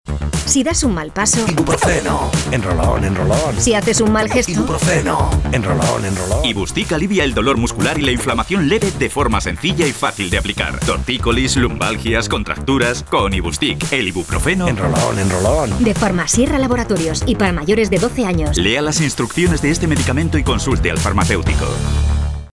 Cuña Radio Ibustick